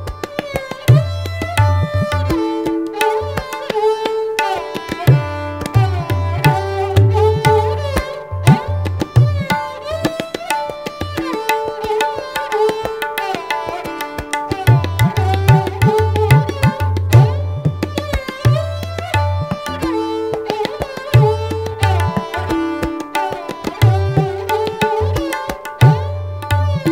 Category: Tabla Ringtones